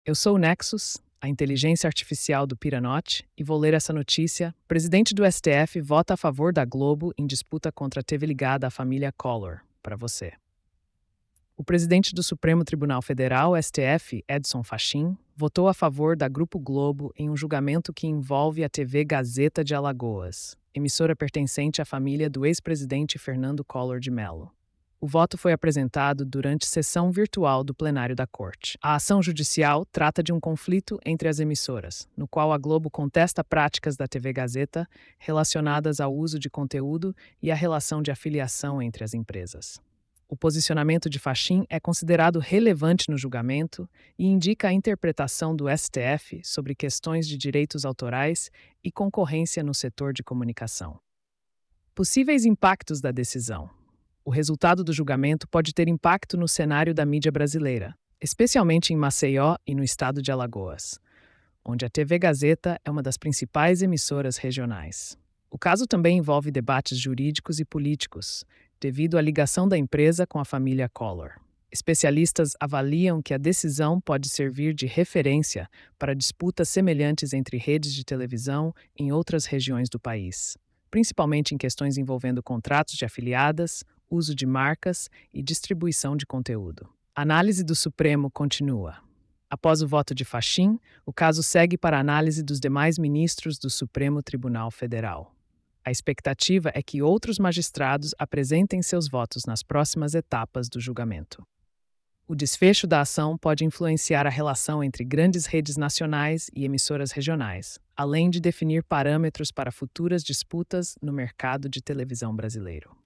Nexus AI · Ouca esta noticia · 2:27